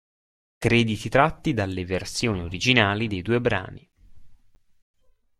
Pronounced as (IPA) /ˈdal.le/